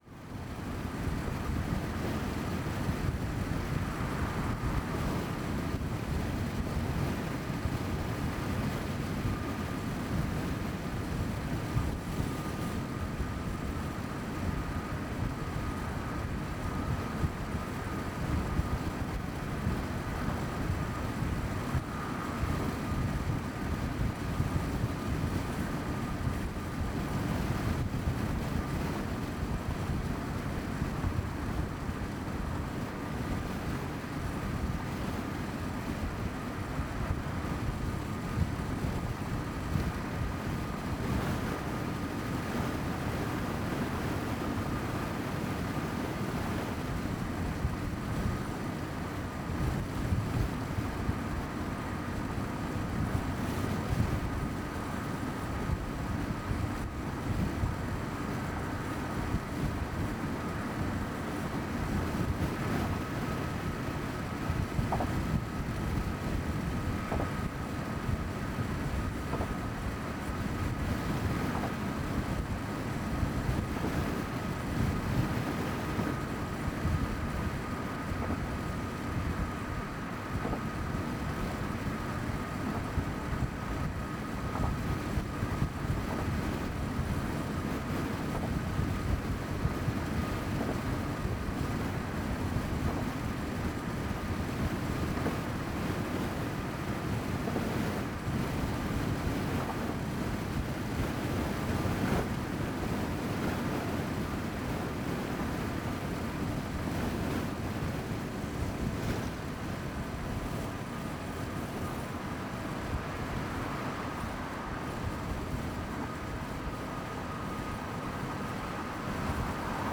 Vehicle SUV Freeway Windows Open 80mph 01_ambiX.wav